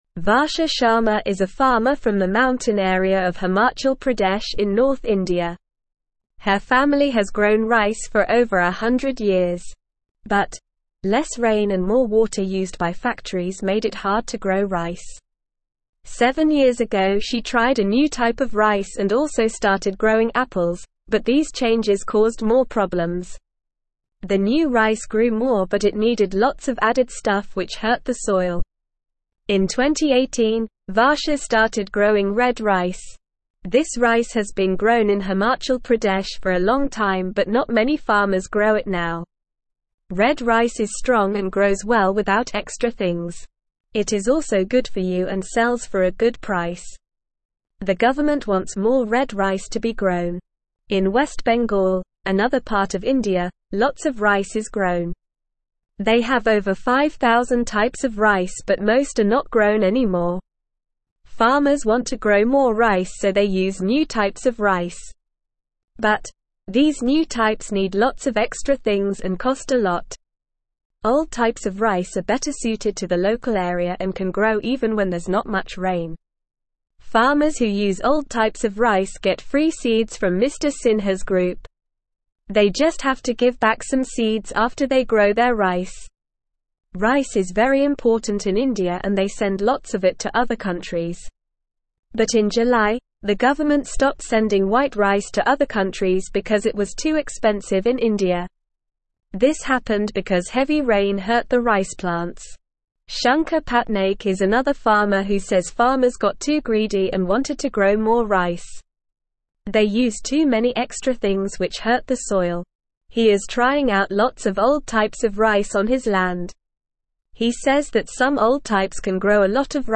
Normal
English-Newsroom-Beginner-NORMAL-Reading-Indian-Farmers-Try-New-Ways-for-Rice.mp3